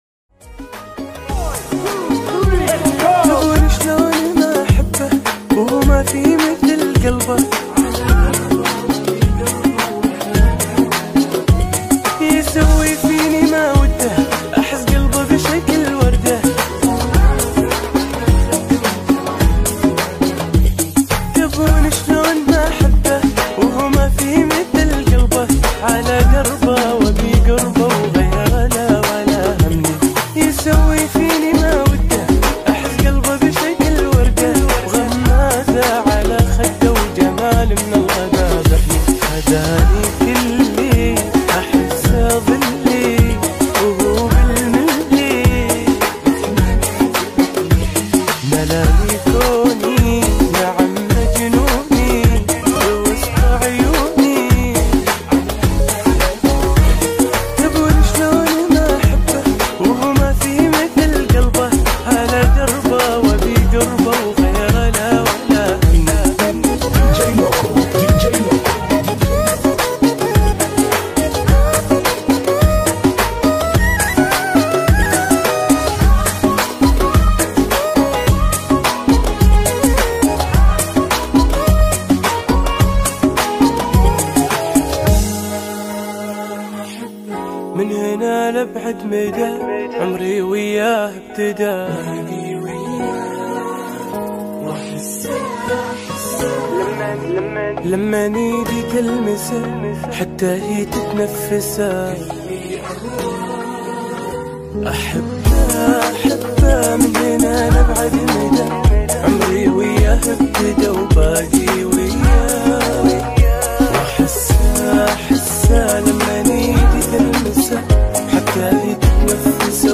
ريمگـس